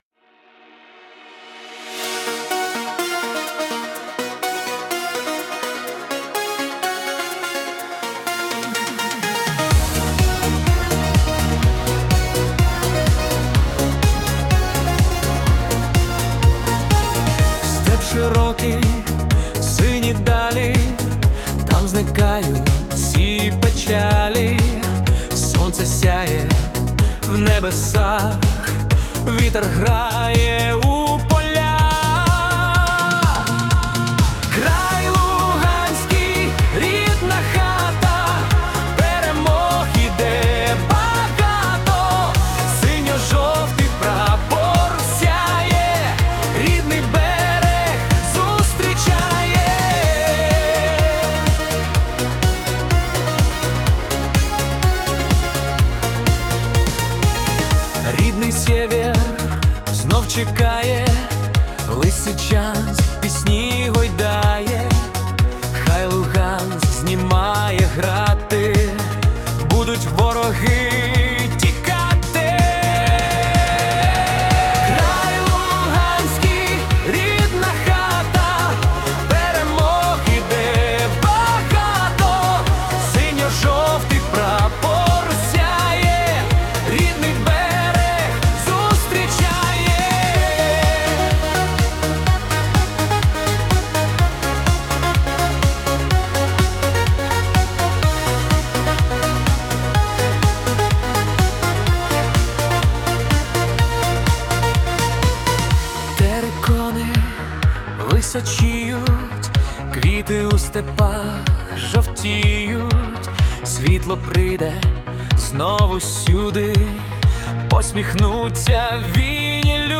🎵 Жанр: Синті-поп / Танцювальний
це запальний трек у стилі синті-поп